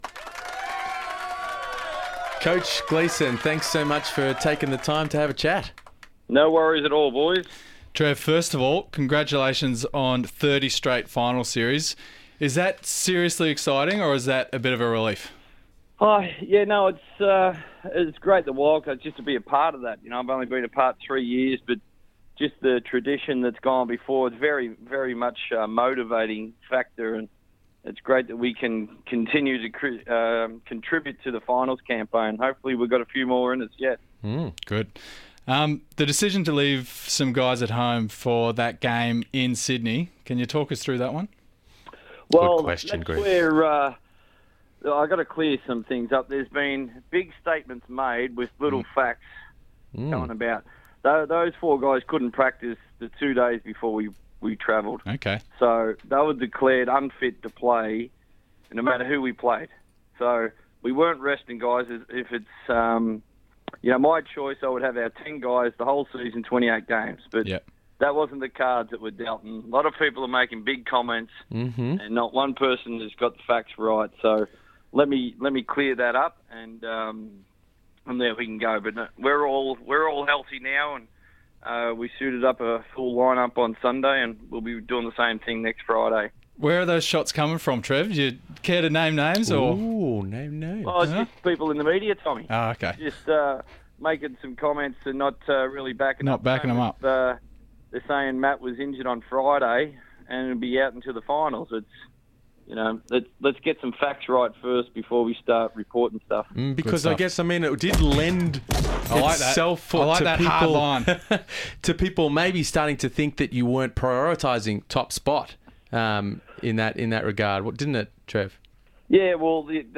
Trevor Gleeson Interview